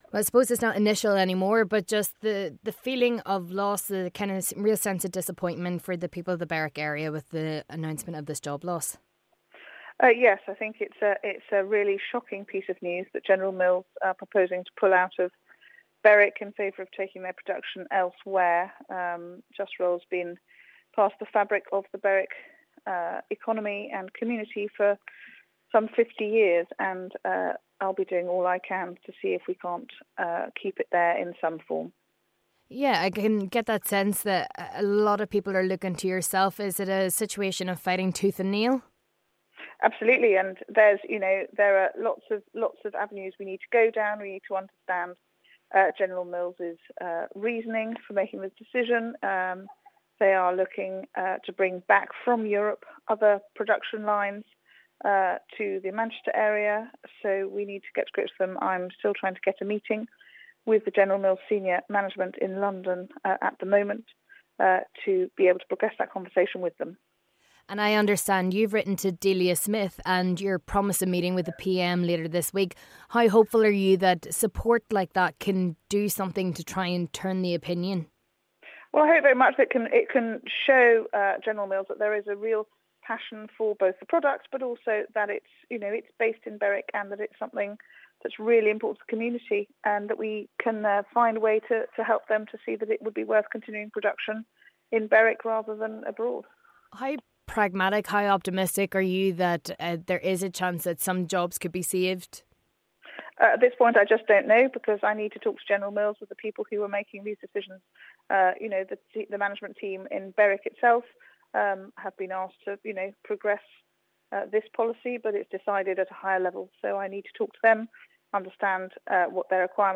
Full Interview with Berwick MP Anne-Marie Trevelyan Over General Mills Closure